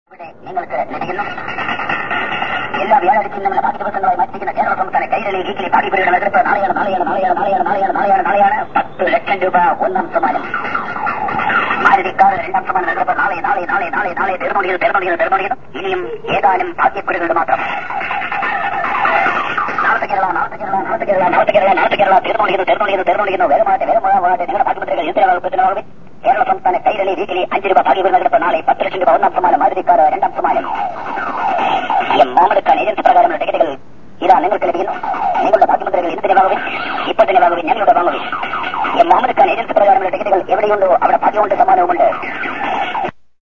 I couldn't believe my ears as I walked past this Alleppey street vendor. He had his radio tuned to some station and was blasting this crazy stuff out into the street.
The only word I can make out is "Kerala".
alleppey_newscast.mp3